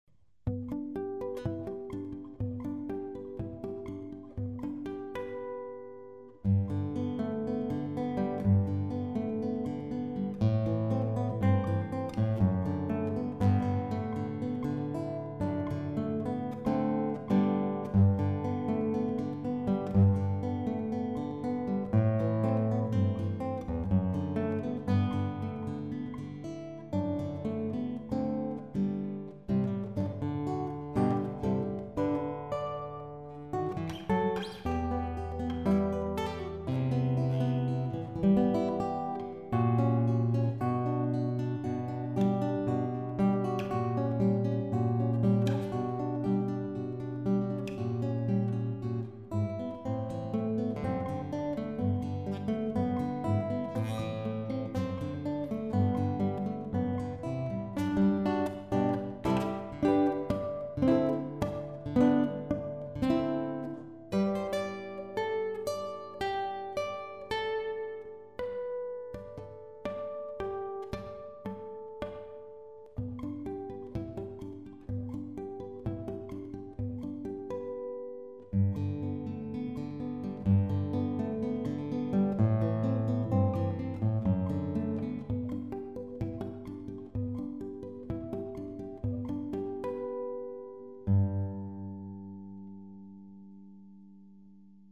So für das stille Kämmerlein fand ich das akustische Ergebnis schon erstaunlich akzeptabel, hier ein Beispiel:
Das ist eine Hanika Konzertgitarre mit Fishman Acoustik Matrix Natural Tonabnehmer, direkt in Line in (ohne EQ), Hall via Cubase, keine weitere BEarbeitung. (Ok, ich werd am Ende zu schnell, war auch nur für einen Test gedacht)